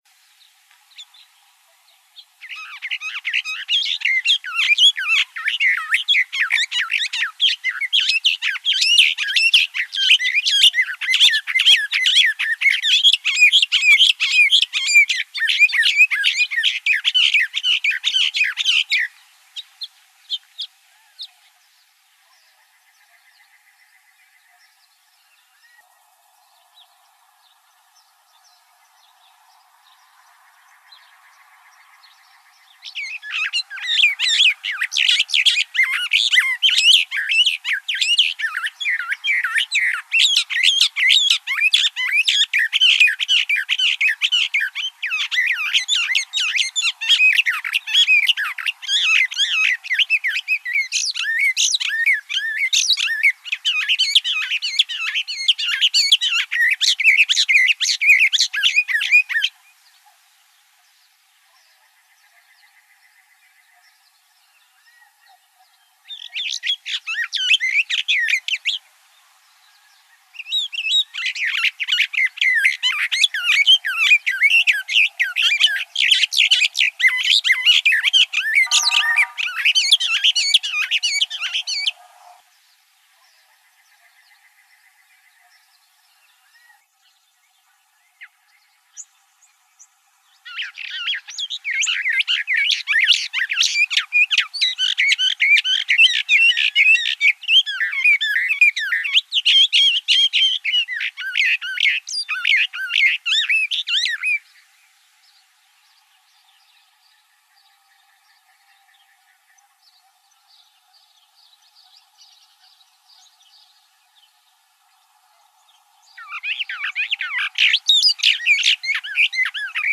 bendiresthrasher.wav